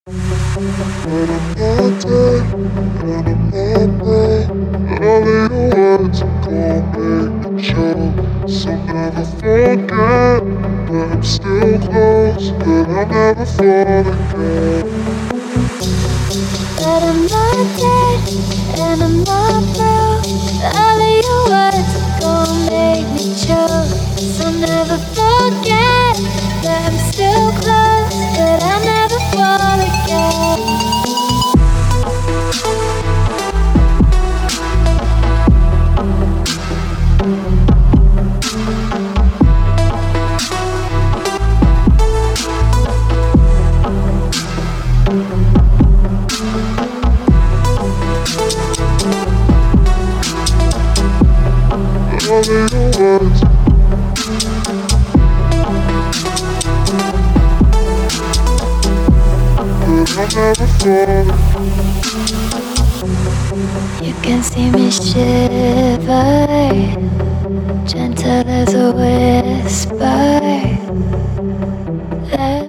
Chillstep mix